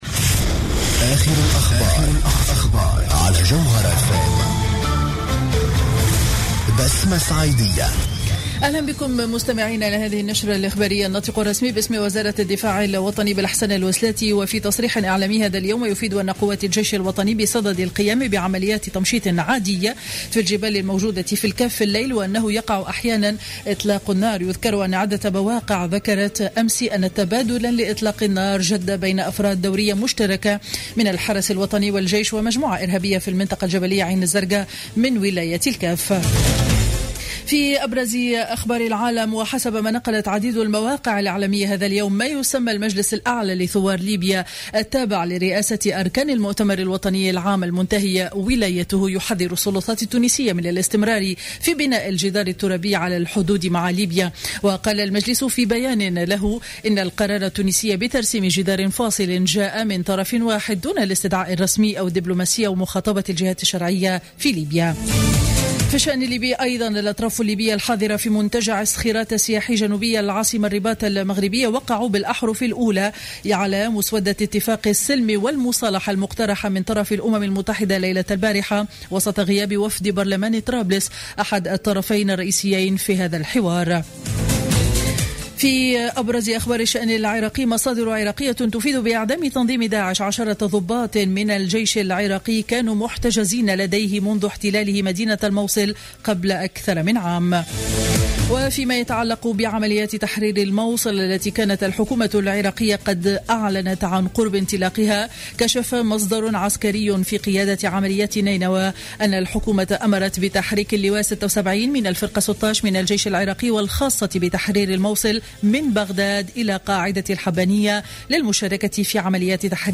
نشرة أخبار منتصف النهار ليوم الأحد 12 جويلية 2015